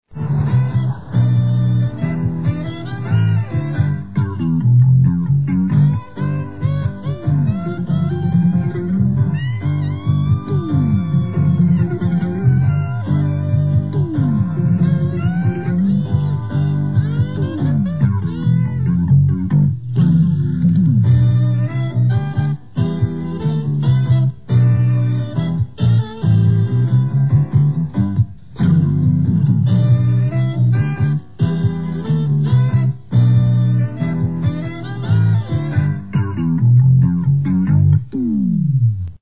Closing theme.